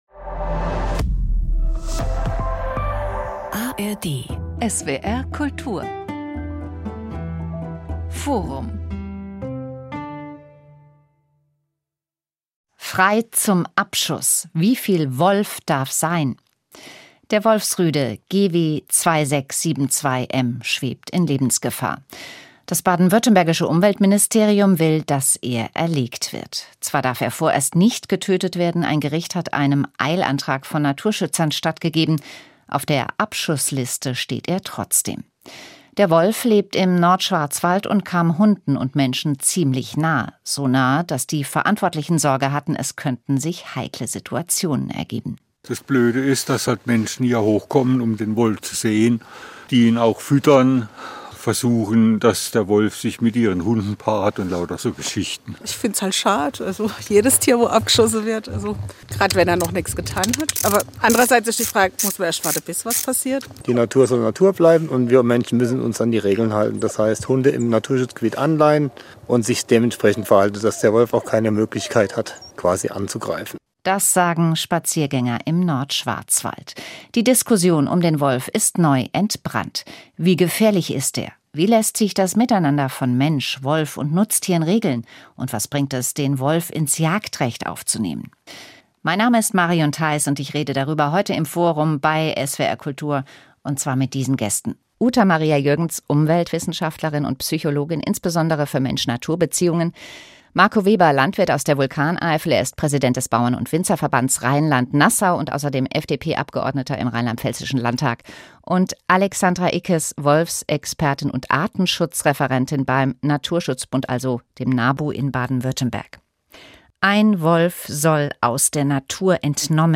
diskutiert